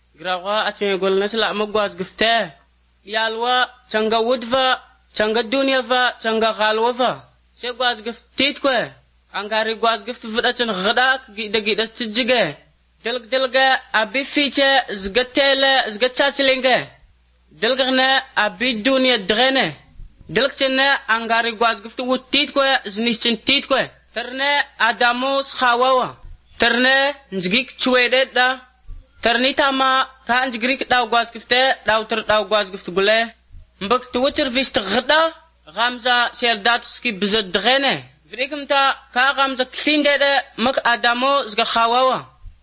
26 June 2016 at 6:30 pm I’m hearing a lot of back of the throat consonants ad a distinction between voicing, nonoicing, and aspiration.